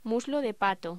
Locución: Muslo de pato
voz